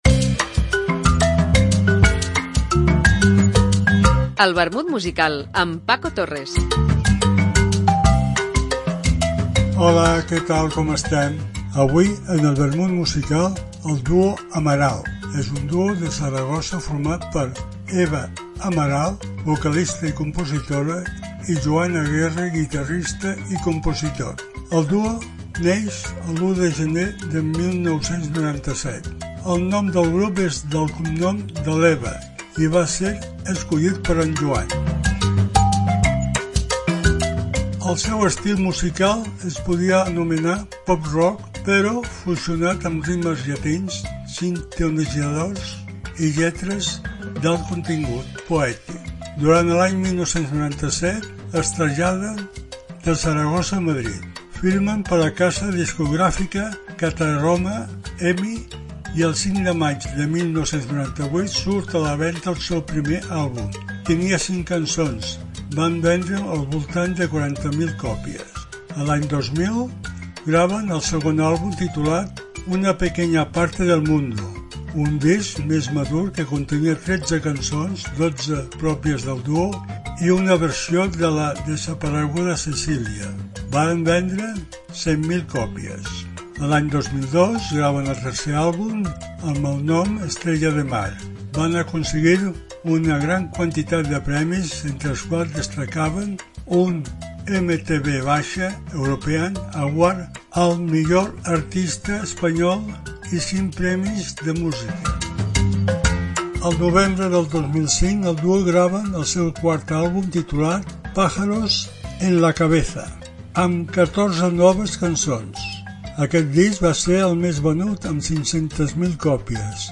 Una apunts biogràfics acompanyats per una cançó